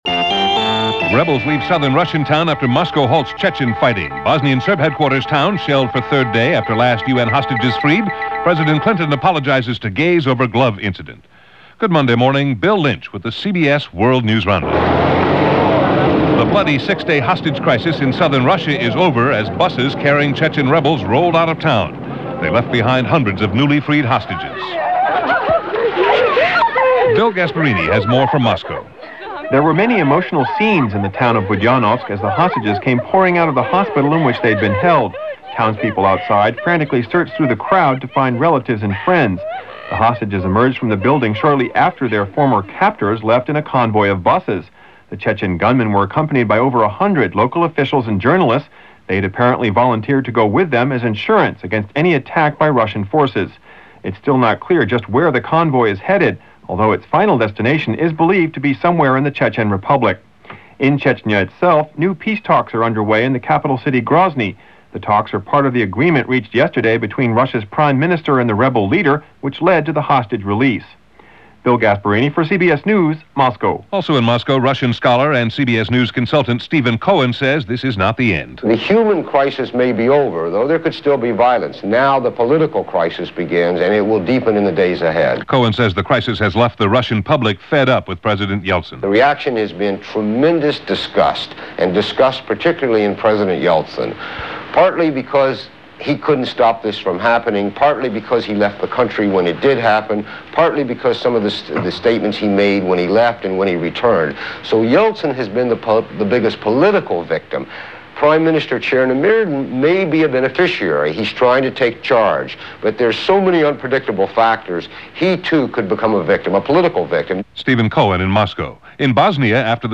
CBS World News Roundup – June 19,1995 – Gordon Skene Sound Collection